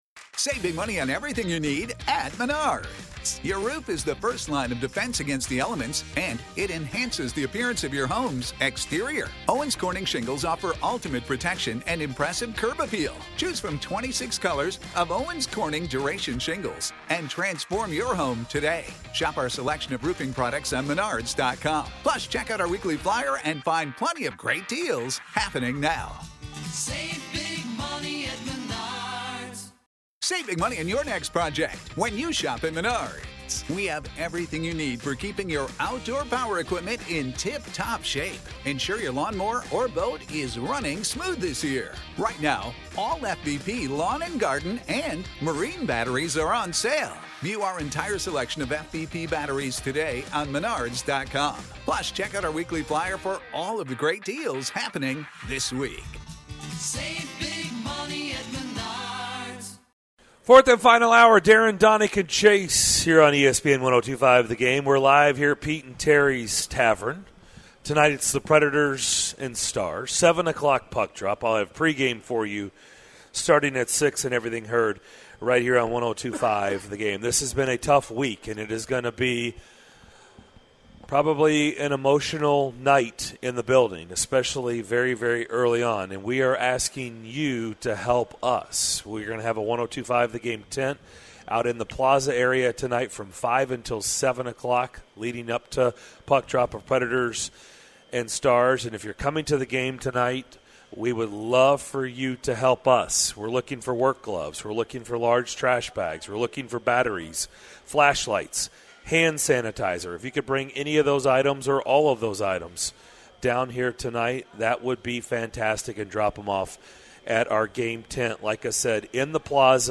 In the final hour of Thursday's DDC: Elliotte Friedman with Sportsnet joins the show to talk about the Preds and the GM meeting in Boca Raton, Florida.